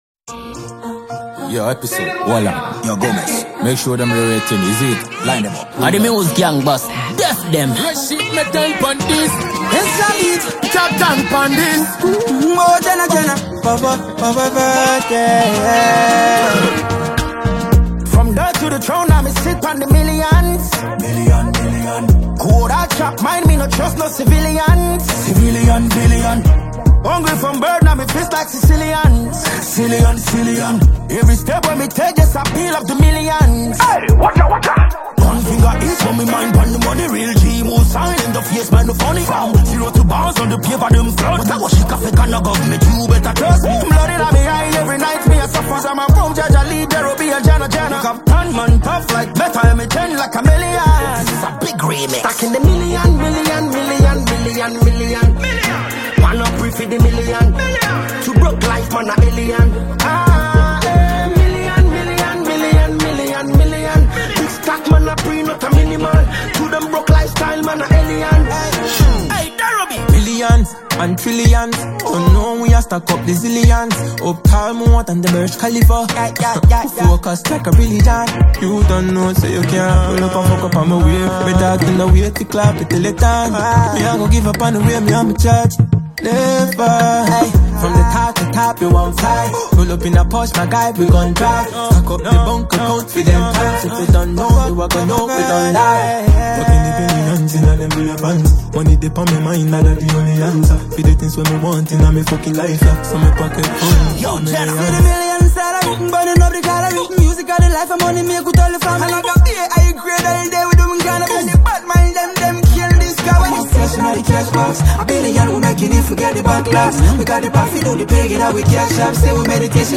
Ghanaian reggae-dancehall